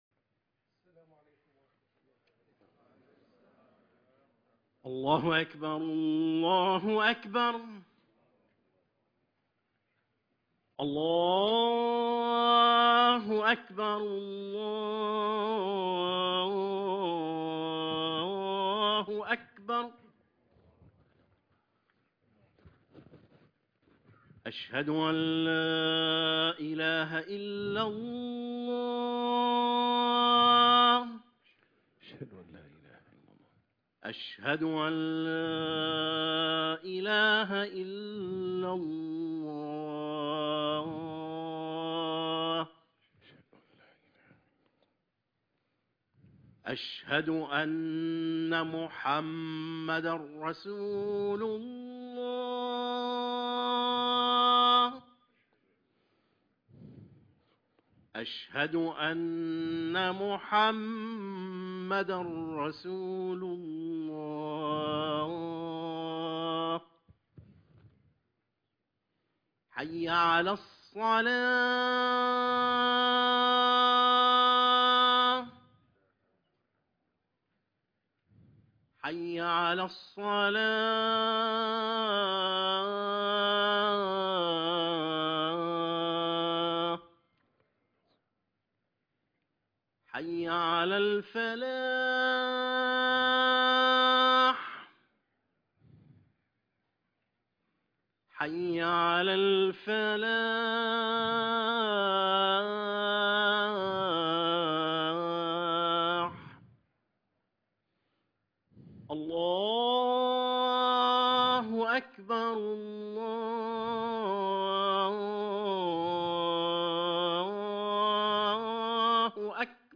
خطبة الجمعة - الايمان بالله